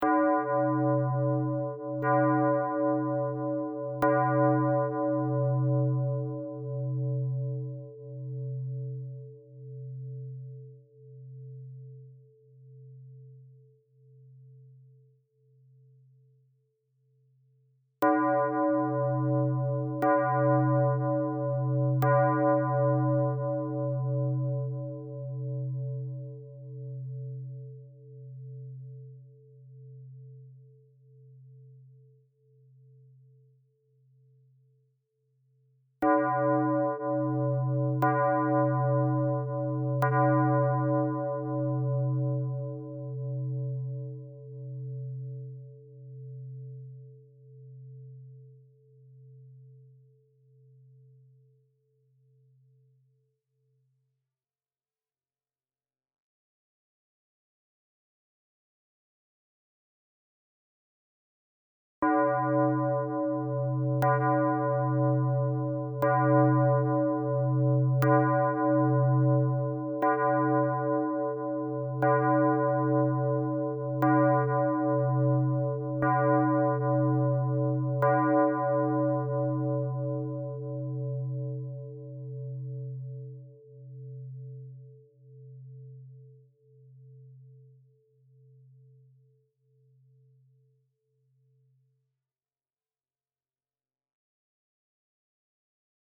Is so peaceful and solemn!
Angelus Bells.mp3